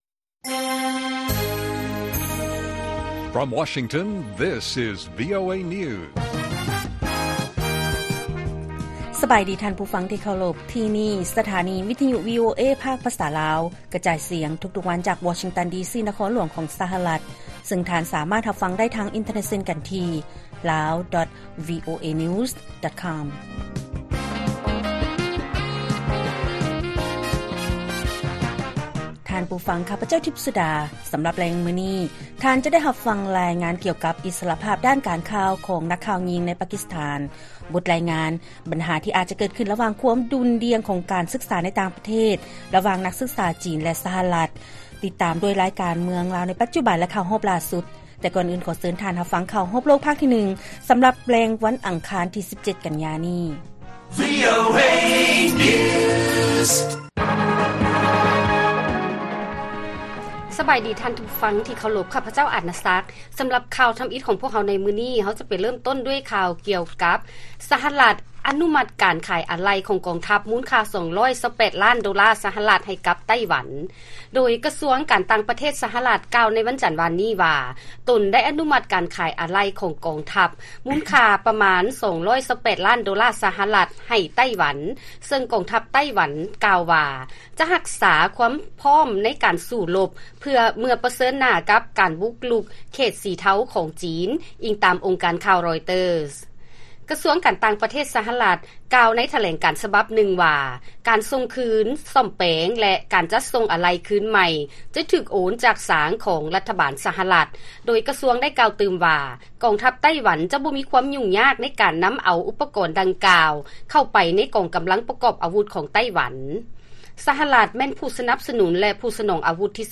ລາຍການກະຈາຍສຽງຂອງວີໂອເອລາວ: ສະຫະລັດ ອະນຸມັດການຂາຍອາໄຫຼ່ຂອງກອງທັບ ມູນຄ່າ 228 ລ້ານໂດລາ ໃຫ້ກັບ ໄຕ້ຫວັນ